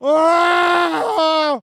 scream_short_3.ogg